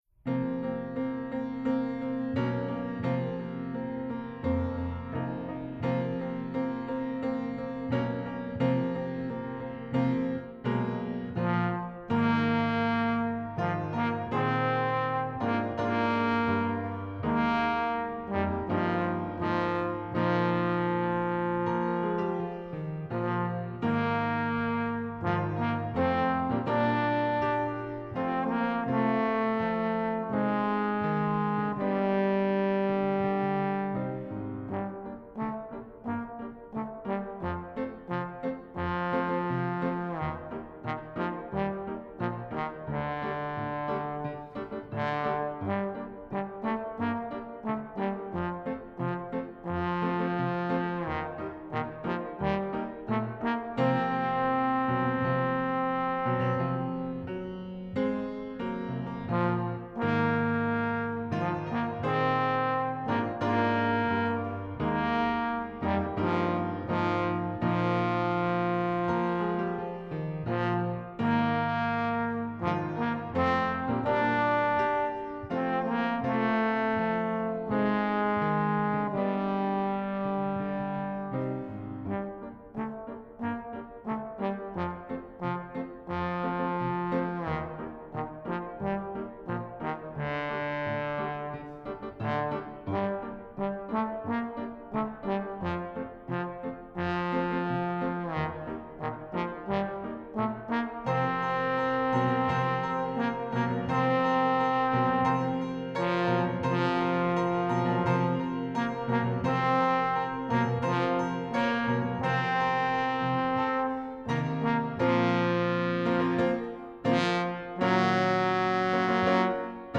Style: Fanfare and Bohemian Lyrical
Instrumentation: Trombone and Piano